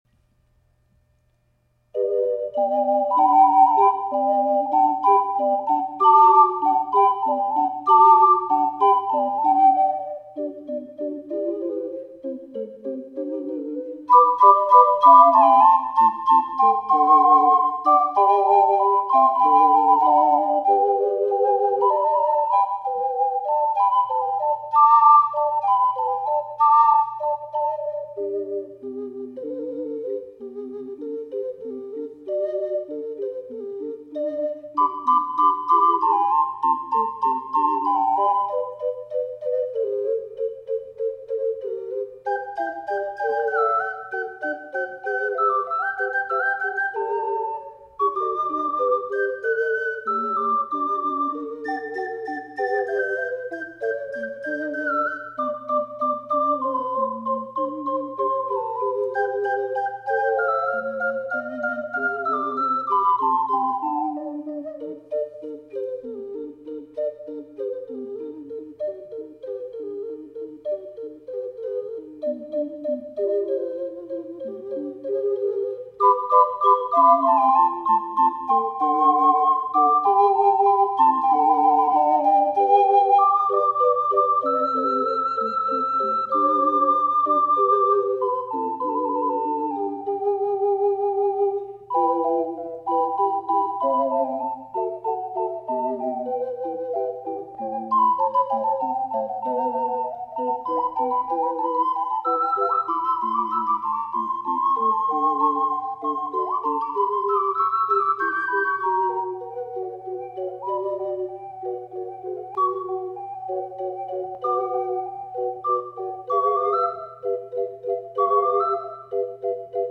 ④BC    楽譜通りの高さです。
トリルは、バロック音楽風（拍の頭から＆一音上の音から始める）を心がけました。
試奏は切り貼りでごまかしていますが、最初から、「Ｅ」「Ｇ」の2ndが何とか吹けるぐらいの速さにするのがいいですね。